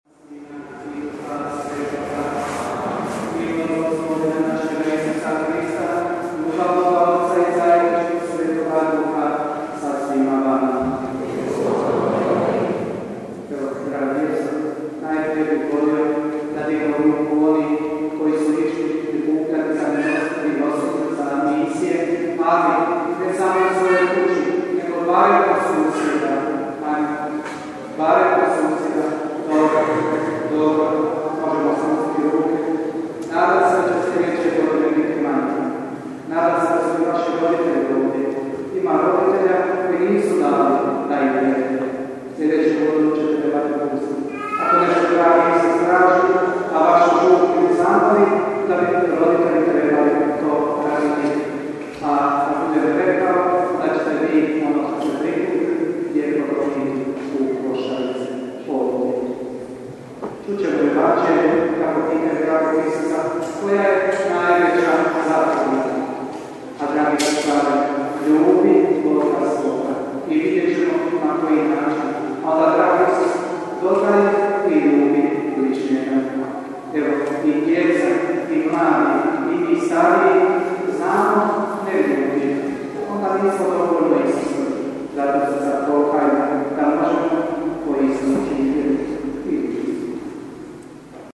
UVODNA MISAO